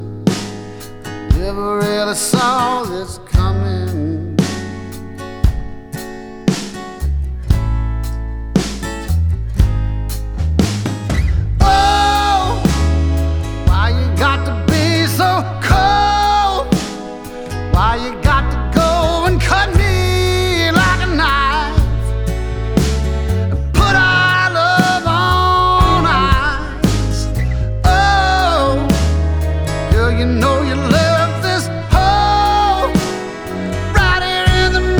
Жанр: Кантри / Русские
# Country